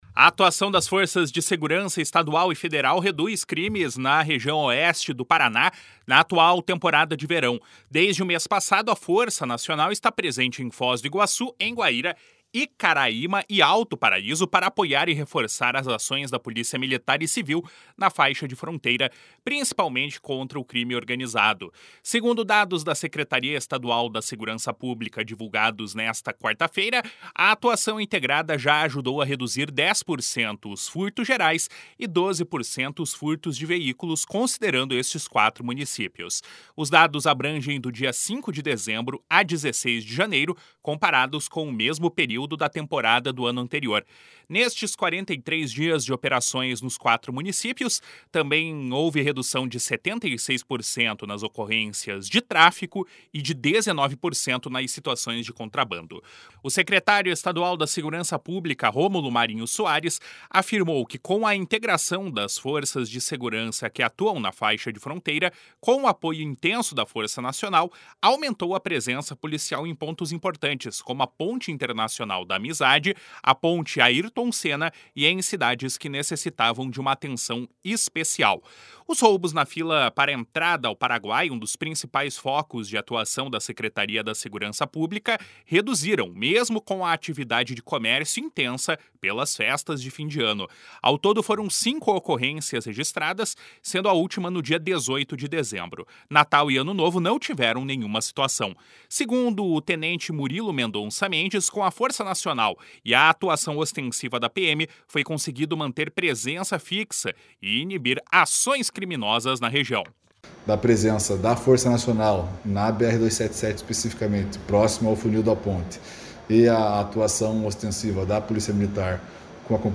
O secretário estadual da Segurança Pública, Romulo Marinho Soares, afirmou que com à integração das forças de segurança que atuam na faixa de fronteira, com o apoio intenso da Força Nacional, aumentou a presença policial em pontos importantes, como a Ponte Internacional da Amizade, Ponte Ayrton Sena e em cidades que necessitavam de uma atenção especial.